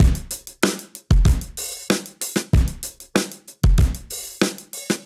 Index of /musicradar/sampled-funk-soul-samples/95bpm/Beats
SSF_DrumsProc1_95-01.wav